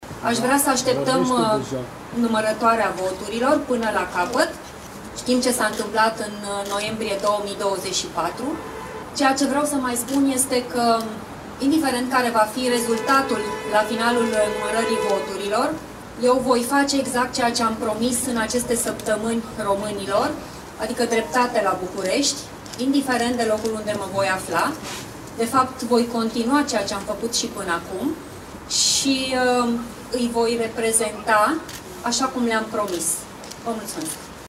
Anca Alexandrescu, candidat independent susținută de AUR și PNȚCD, a oferit primele declarații după apariția rezultatelor exit-poll. Ea susține că trebuie așteptate rezultatele finale.